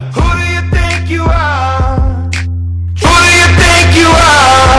Who Do You Think You Are Estourado Botão de Som